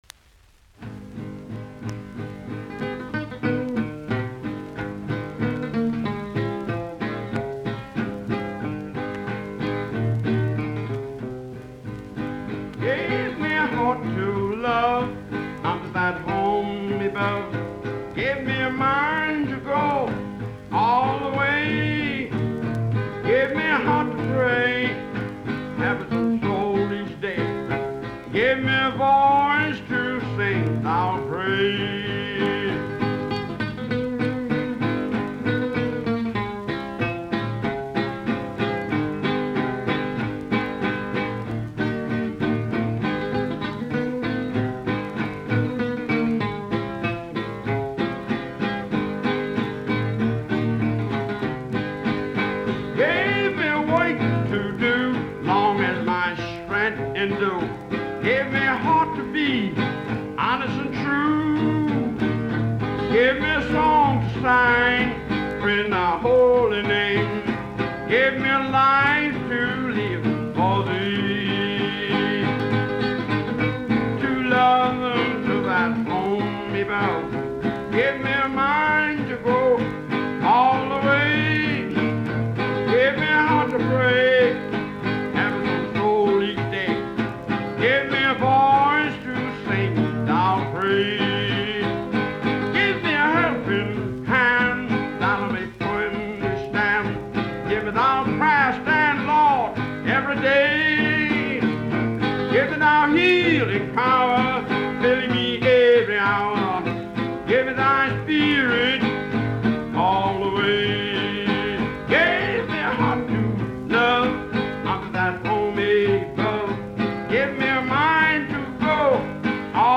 Music from the south : field recordings taken in Alabama, Lousiana and Mississippi.
piano: Sweeter as the days go by